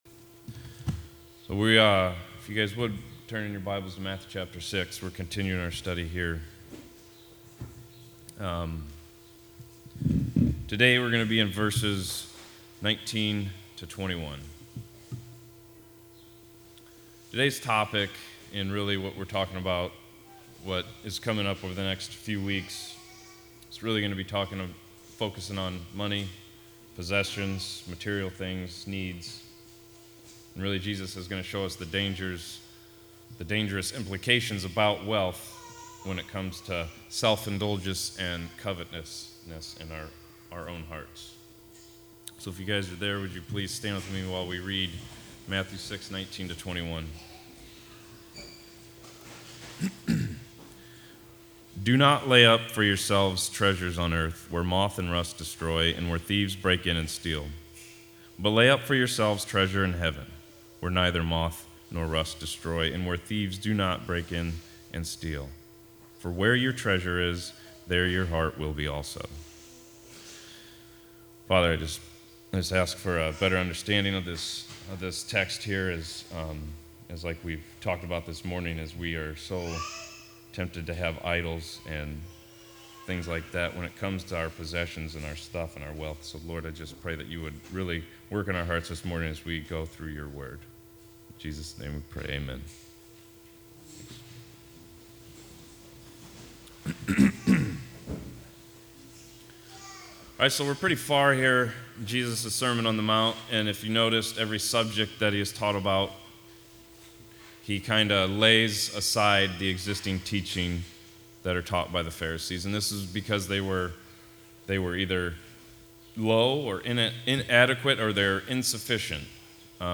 Covenant Reformed Fellowship Sermons